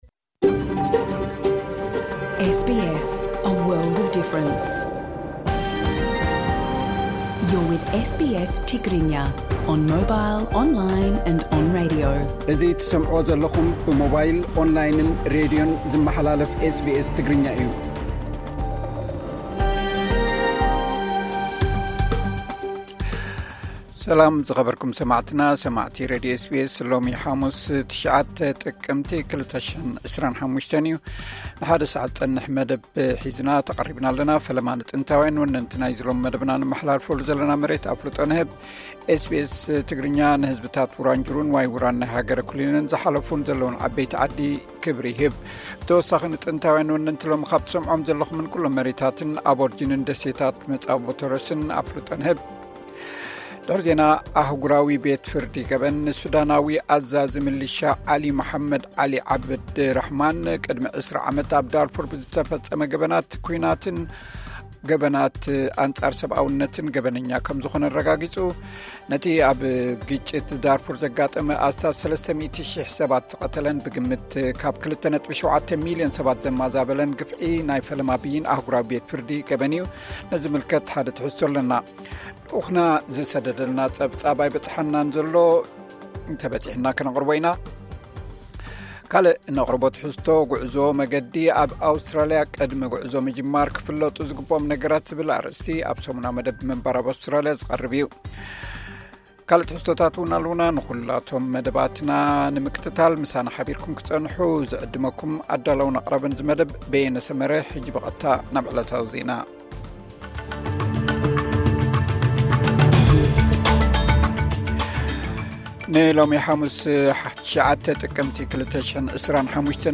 ቀጥታ ምሉእ ትሕዝቶ ኤስ ቢ ኤስ ትግርኛ (09 ጥቅምቲ 2025)